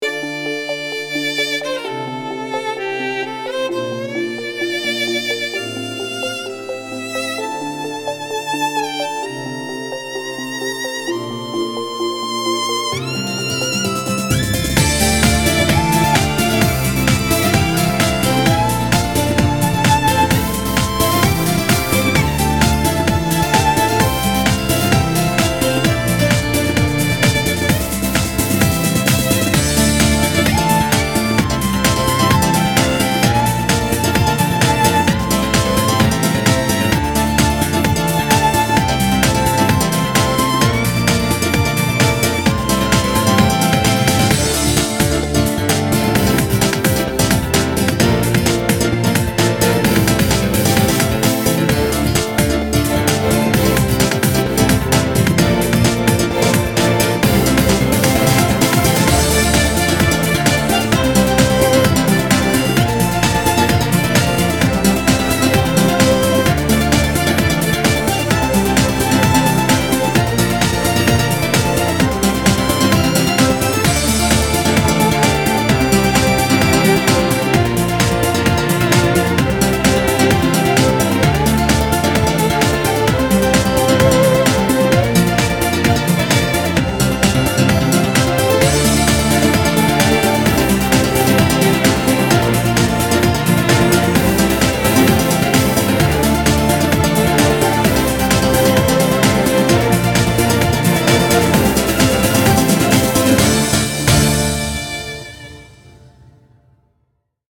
BPM130
Audio QualityPerfect (High Quality)
Genre: ETHNIC GROOVE.